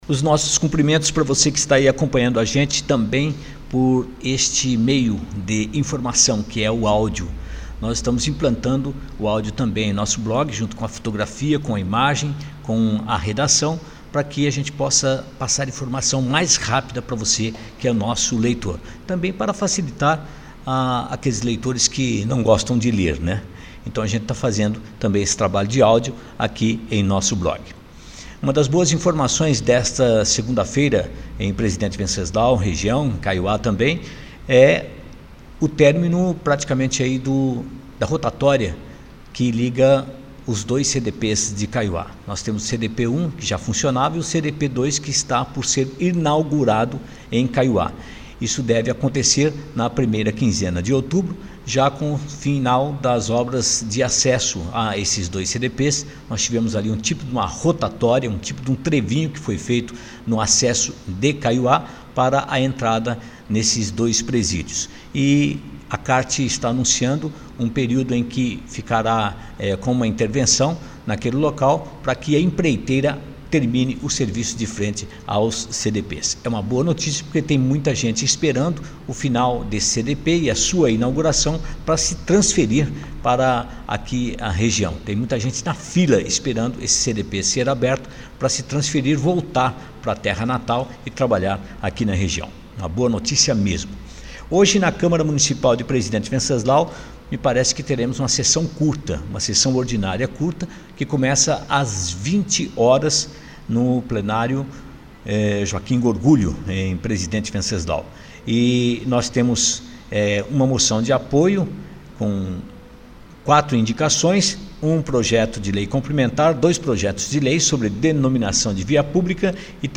Vamos fazer gravações de textos de maneira rápida, para que as notícias chegam da forma que precisam para os nossos leitores.